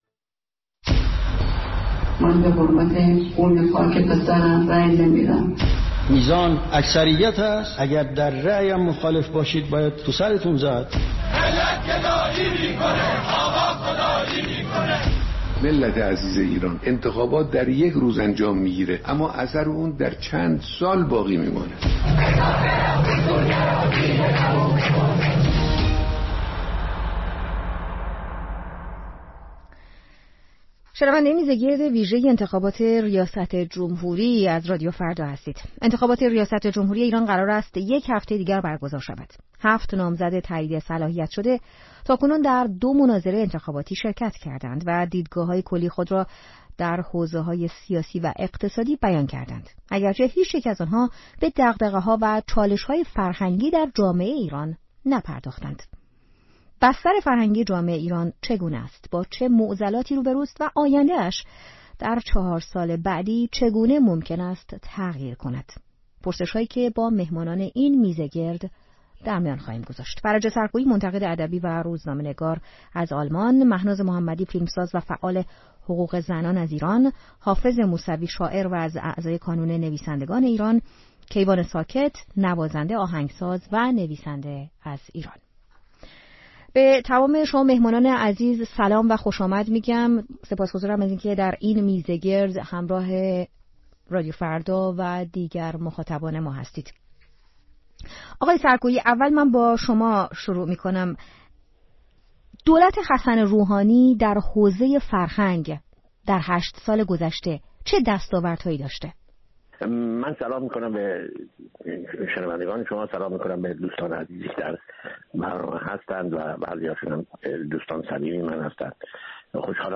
میزگرد ویژه انتخابات: کارنامه فرهنگی دولت روحانی و چالش‌های پیش رو